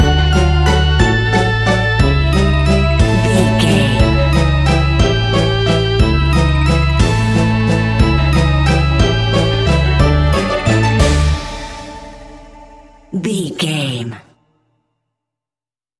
Aeolian/Minor
scary
ominous
eerie
playful
strings
synthesiser
percussion
spooky
horror music